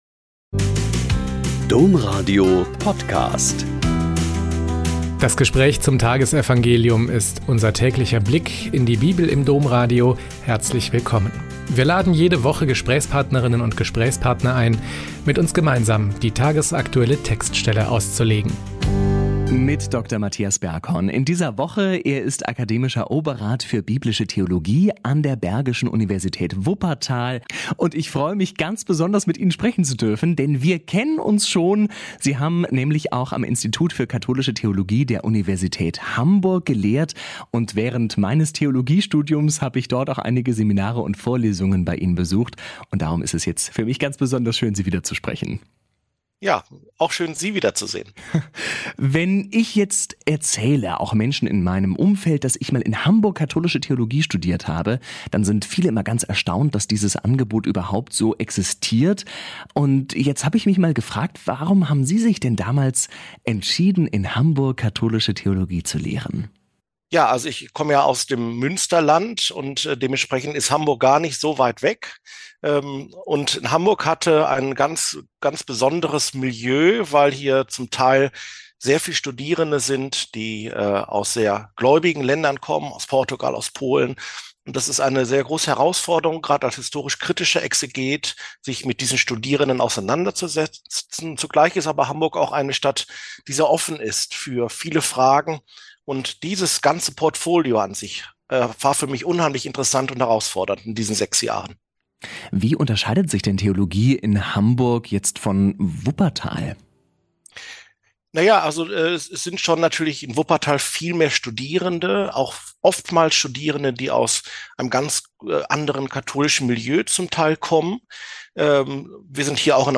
Mt 19, 27-29 - Gespräch